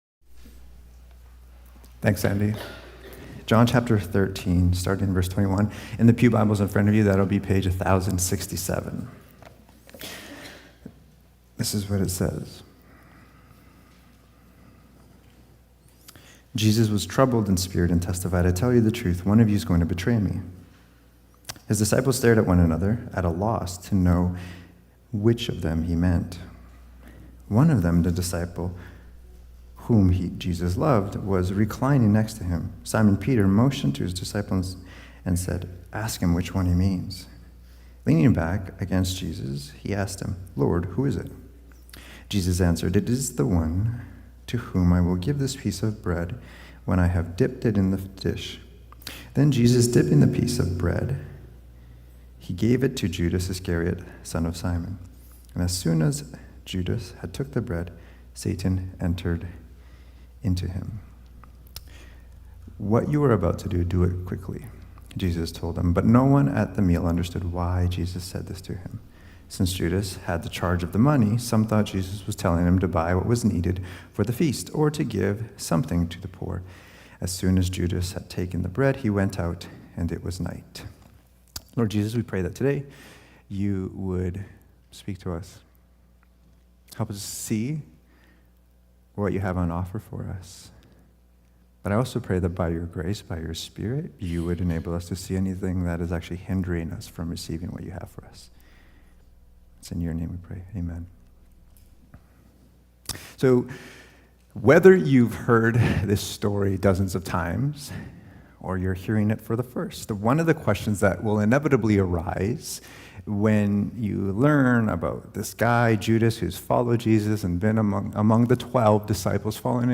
Cascades Church Sermons Desire and the Bread of Life Play Episode Pause Episode Mute/Unmute Episode Rewind 10 Seconds 1x Fast Forward 30 seconds 00:00 / 33:30 Subscribe Share Apple Podcasts RSS Feed Share Link Embed